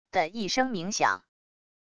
的一声鸣响wav音频